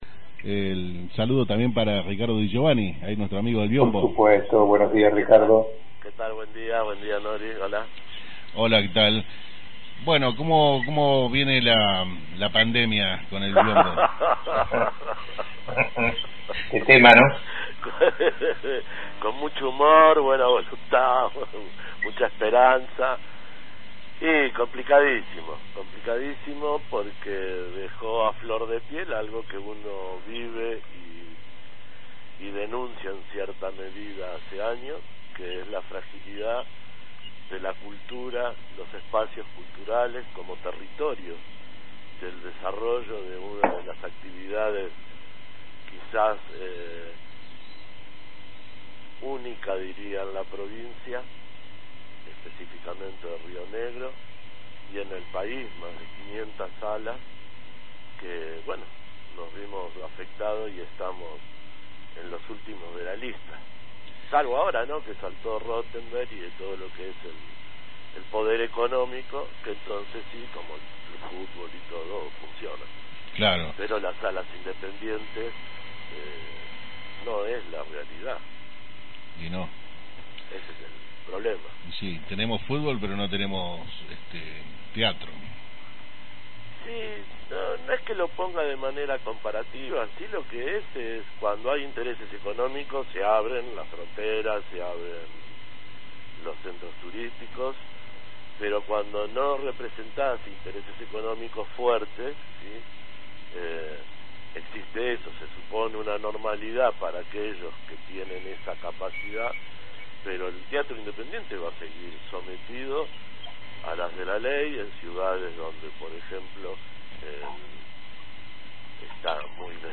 En conversación telefónica